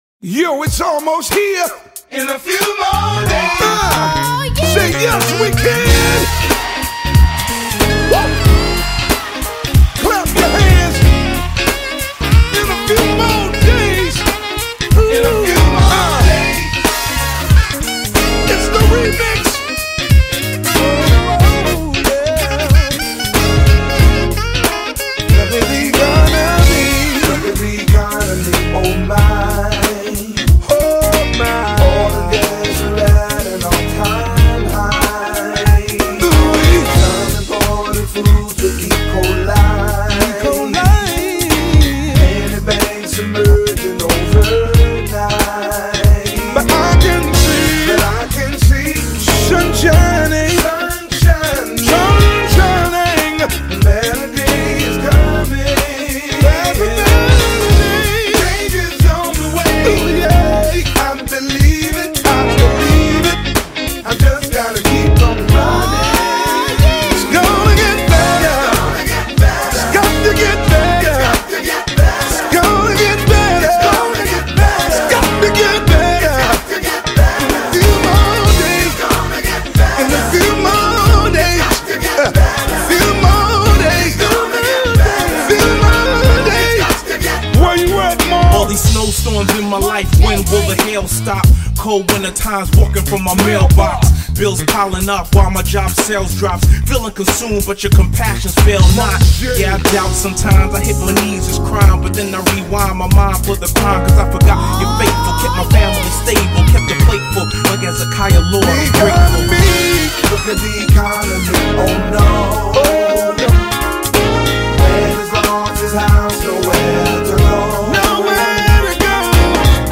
signature vocals
unique blend of gospel and R&B